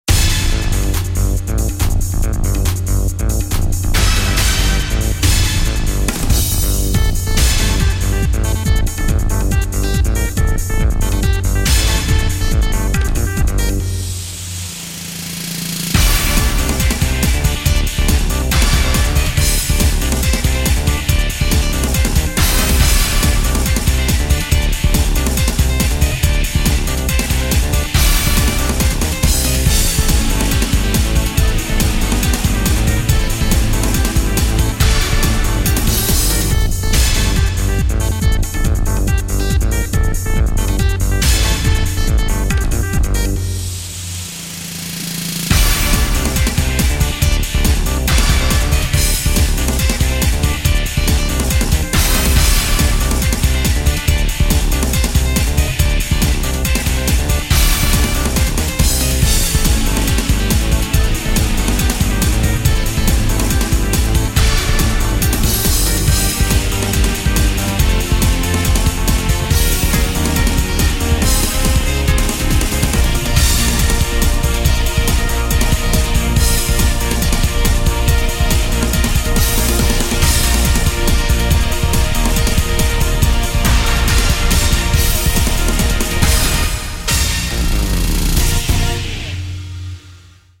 片头音乐